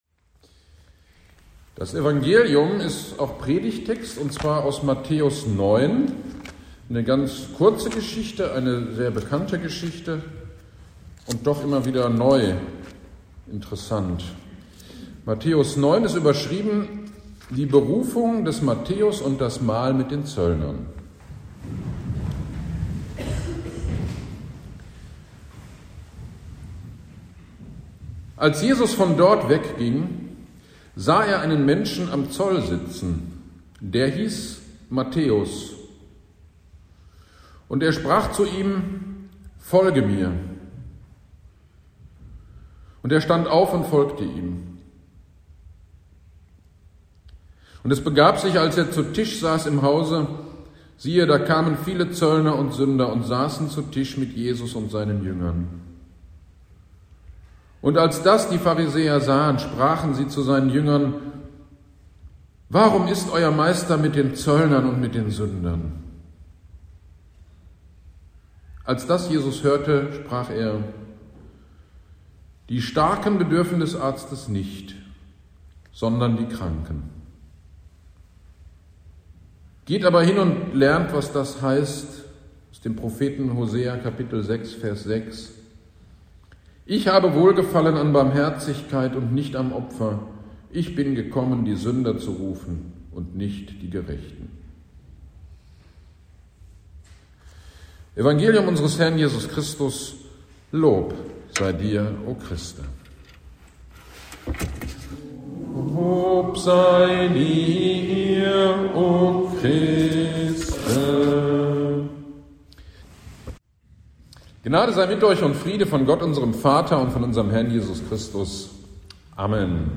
Gottesdienst am 05.02.2023 Predigt zu Matthäus 9.9-13 - Kirchgemeinde Pölzig